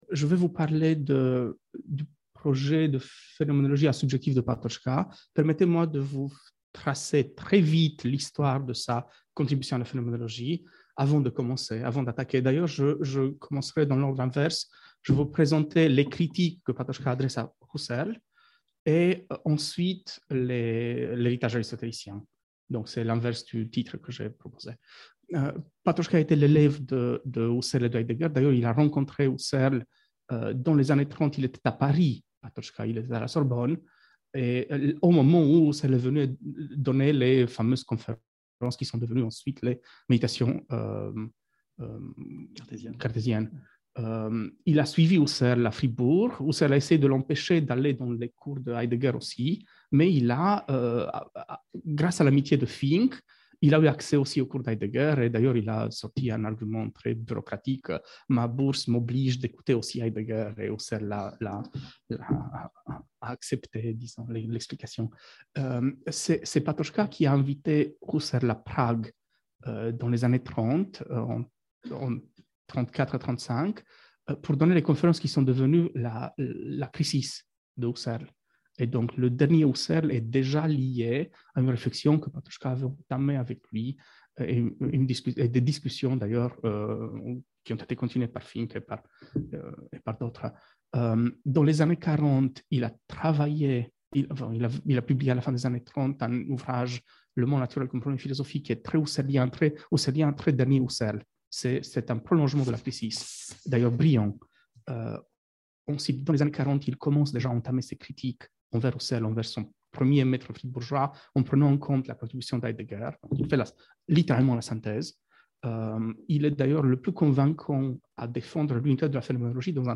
Cette conférence proposée dans le cadre du colloque sur Jan Patočka montre l’originalité de la perspective phénoménologique de Patočka, notamment dans son rapport avec la pensée d’Eugène Fink. En cherchant à contester ce qu’il comprend comme un dédoublement de la sphère phénoménale chez Husserl, Patočka souhaite donner un sens nouveau aux termes d’immanence et de transcendance.